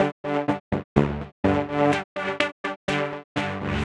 tx_synth_125_richpad_GD.wav